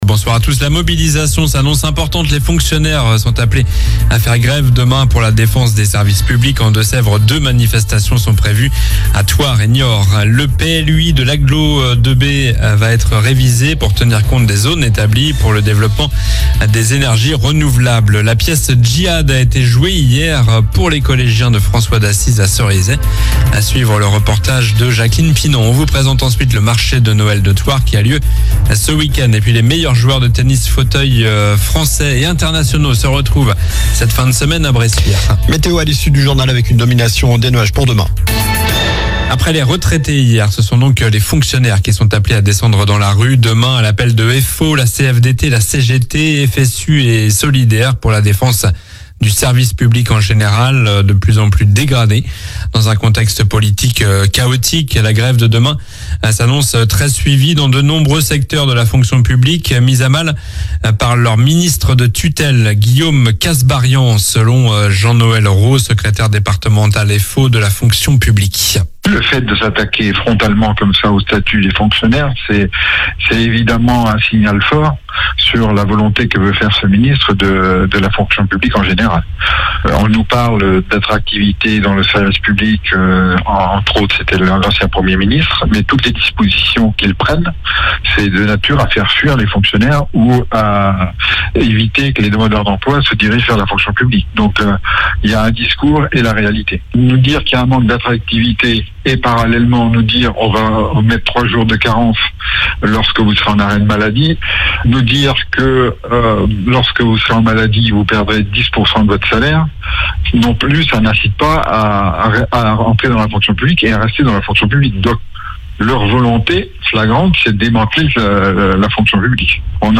Journal du mercredi 04 décembre (soir)